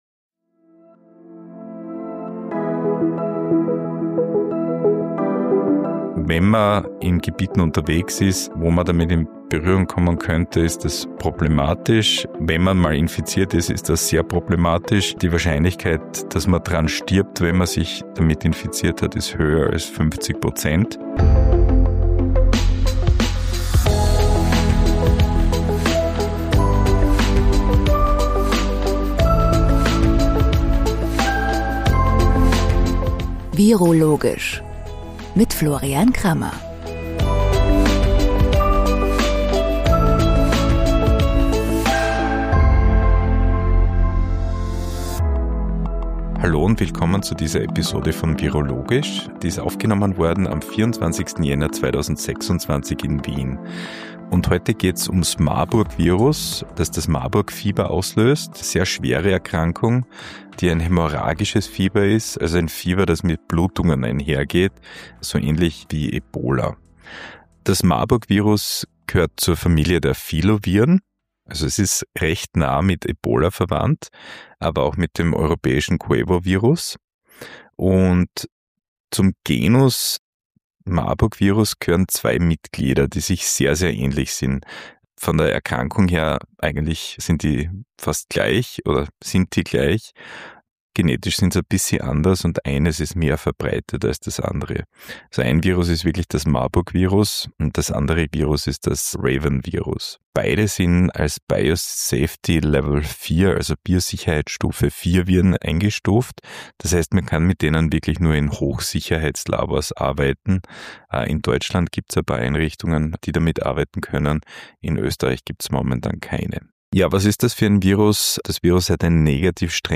Beschreibung vor 2 Monaten In dieser Folge von viroLOGISCH geht es um das Marburg-Virus, einen hochgefährlichen Erreger aus der Familie der Filoviren und einen nahen Verwandten des Ebola-Virus. Florian Krammer erklärt, wie das Virus übertragen wird, welche Symptome es verursacht und warum es trotz seiner hohen Sterblichkeit kein realistisches Pandemievirus ist.